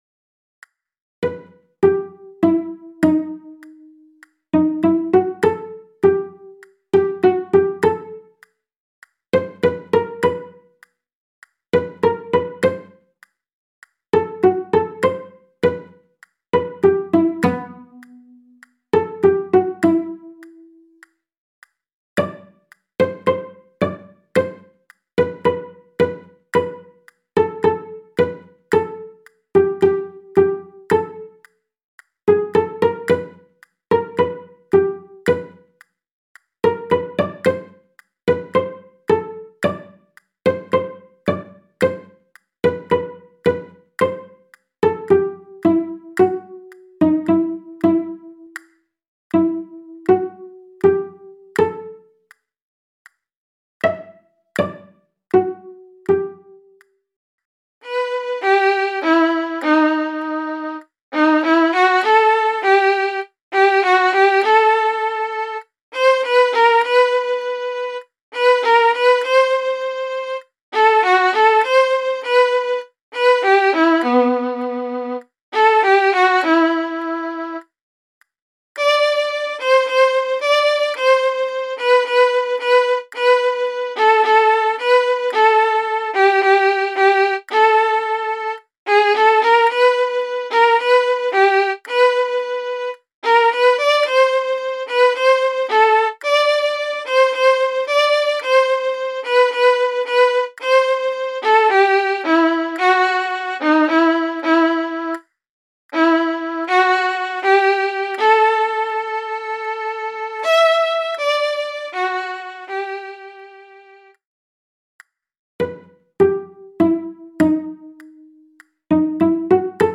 [ Ballade ]
MIDI - 1-stimmig